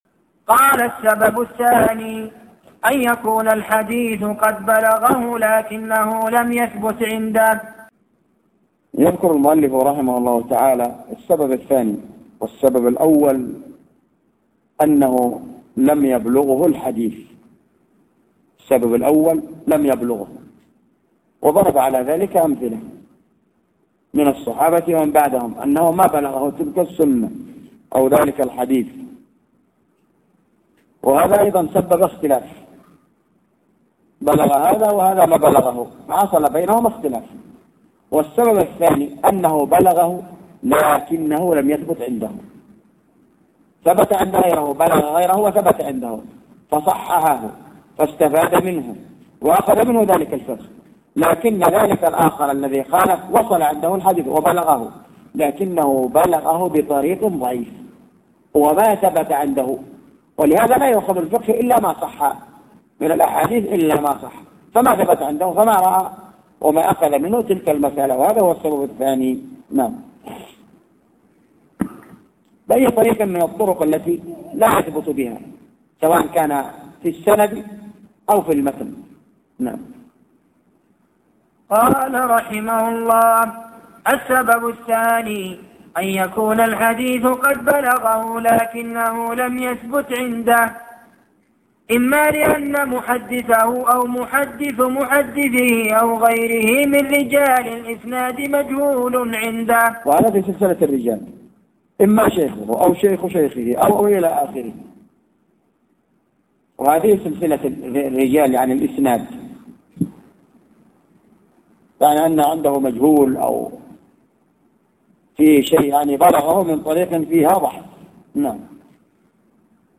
تسجيل لدروس كتاب رفع الملام